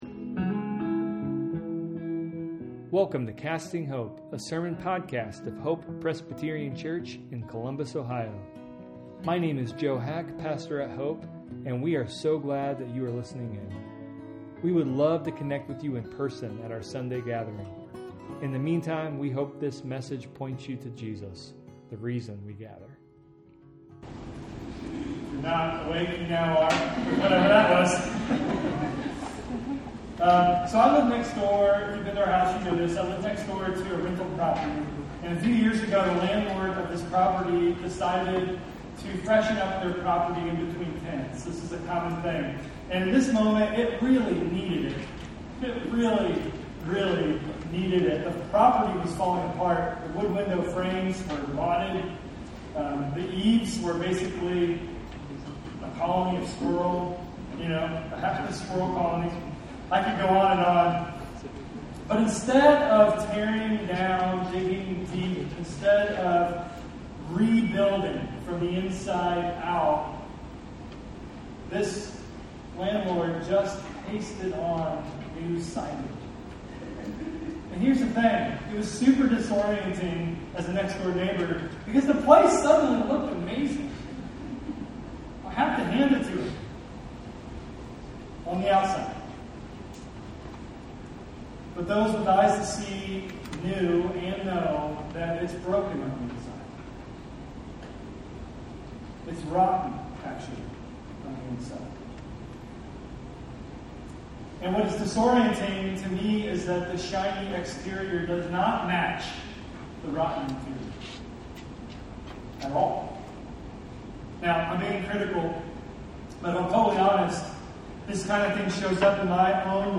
A sermon podcast of Hope Presbyterian Church in Columbus, Ohio.